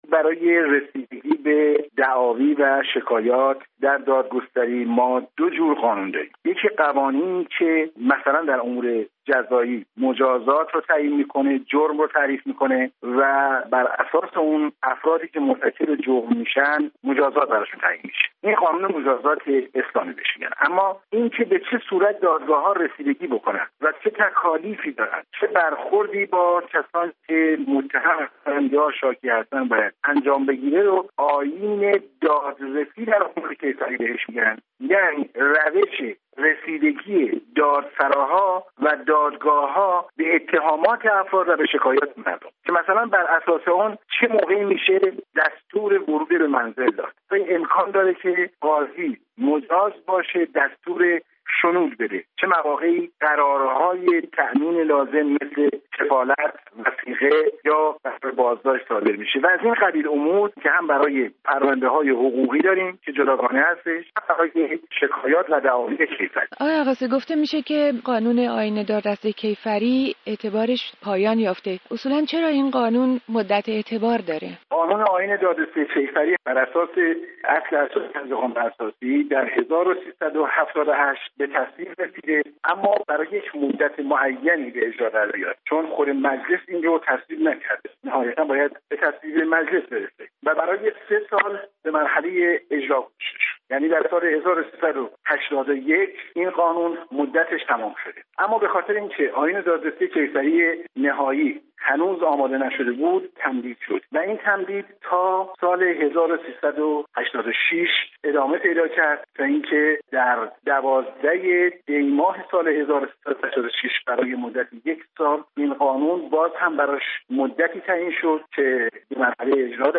گفت‌وگوی رادیو فردا